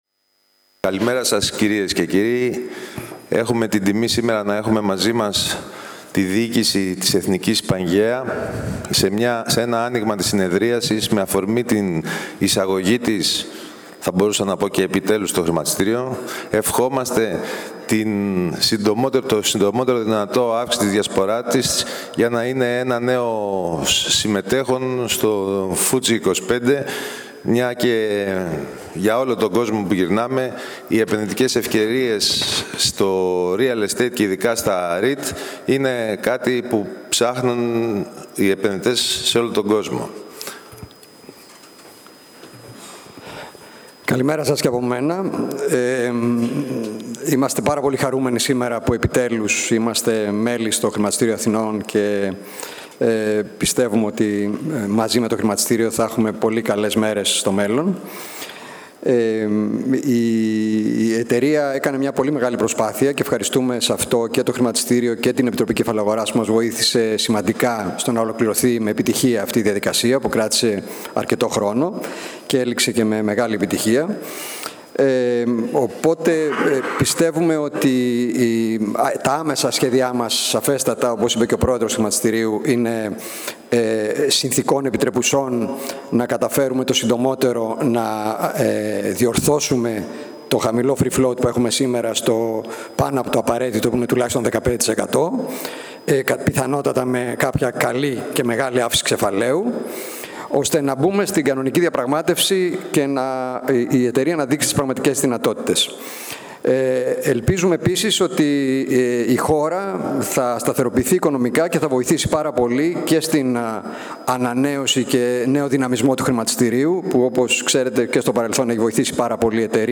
Έναρξη της Συνεδρίασης.